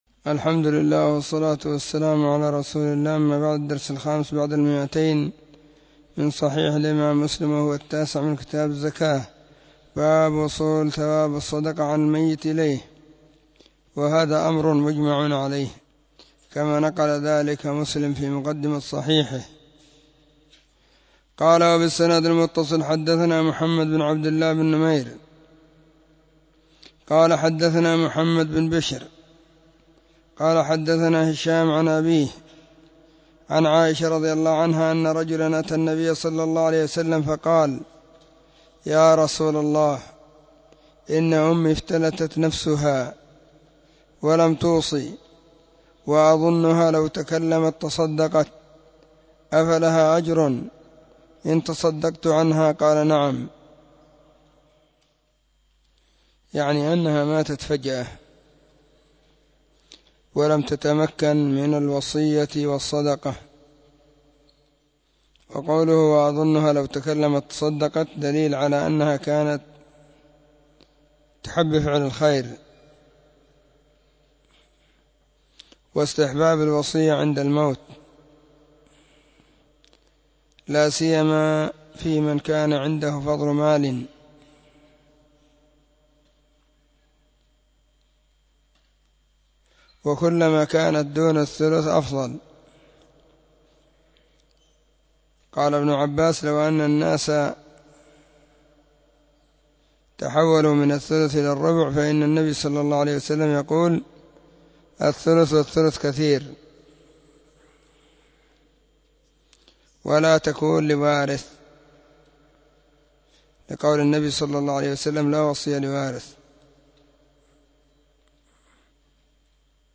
📢 مسجد الصحابة – بالغيضة – المهرة، اليمن حرسها الله.
كتاب-الزكاة-الدرس-9.mp3